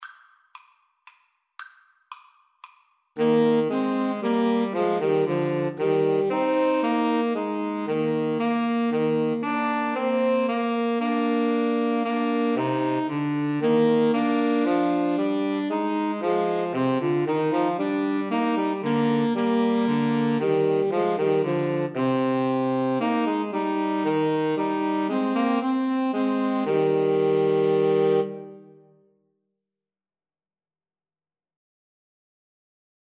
Alto Saxophone 1Alto Saxophone 2Tenor Saxophone
Be Thou My Vision (Irish: Rob tu mo bhoile) is a traditional hymn from Ireland, which is commonly attributed to Dallan Forgaill.
Eb major (Sounding Pitch) Bb major (French Horn in F) (View more Eb major Music for 2-Altos-Tenor-Sax )
3/4 (View more 3/4 Music)
2-Altos-Tenor-Sax  (View more Easy 2-Altos-Tenor-Sax Music)